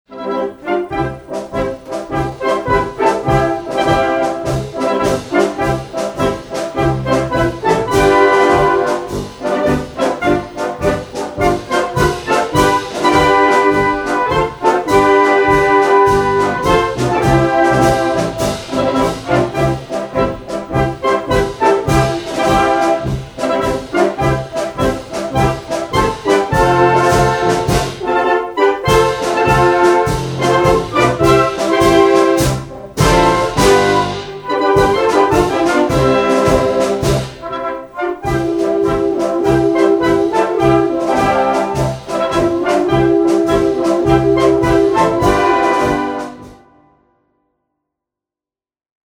für großes Blasorchester